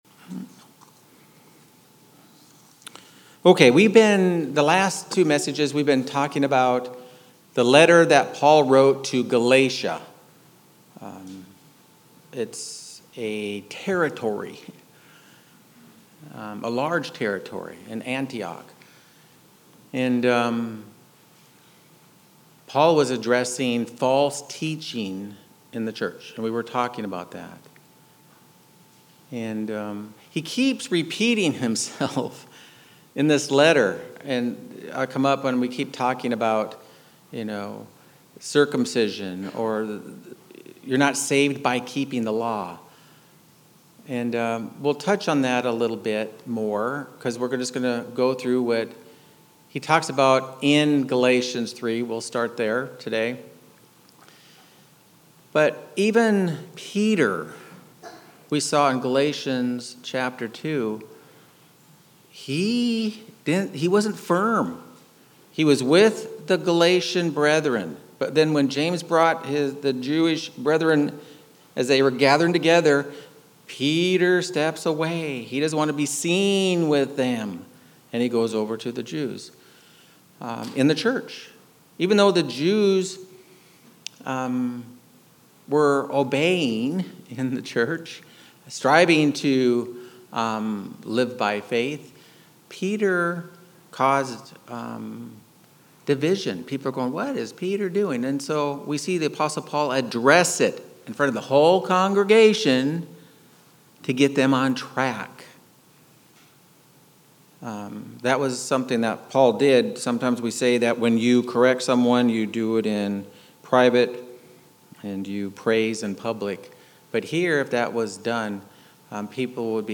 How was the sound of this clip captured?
Given in Phoenix East, AZ